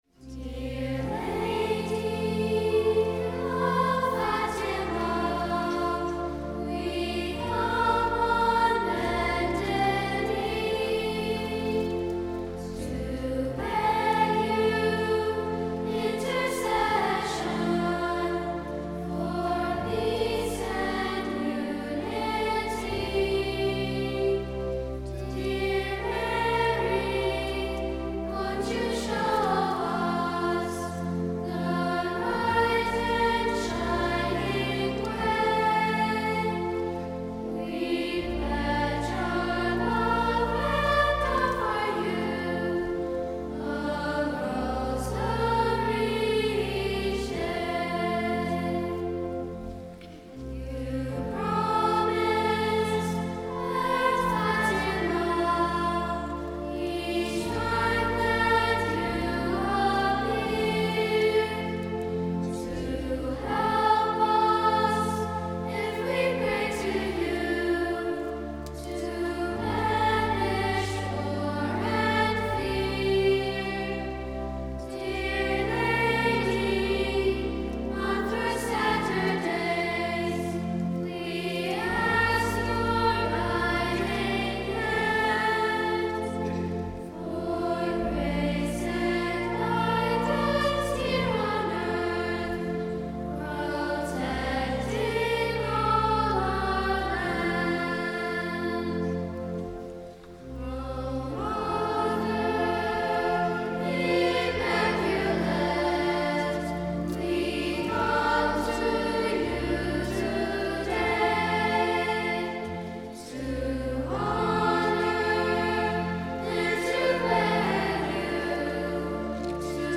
Mp3 Download • Live Children’s Choir Rec.
Ave Maria Parish Children’s Choir
Dear-Lady-of-Fatima-25-Marian-Concert.mp3